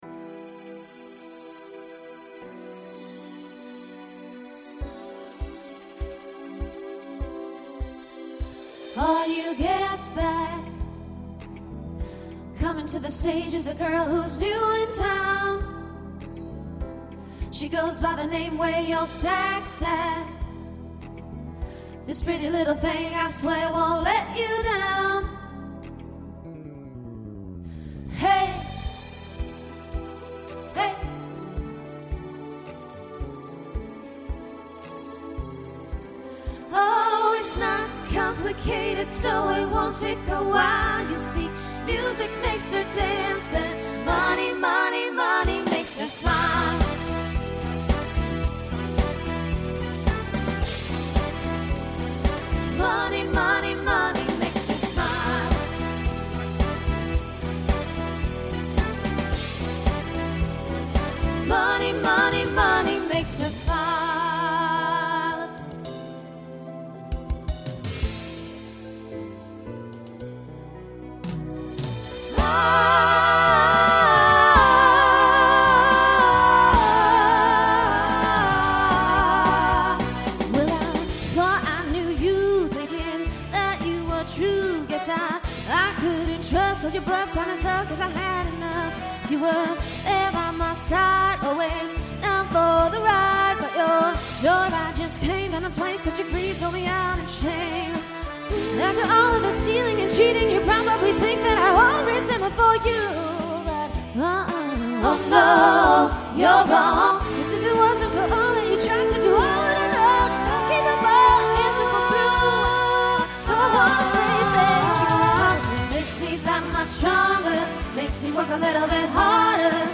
Voicing SSA Instrumental combo Genre Pop/Dance , Rock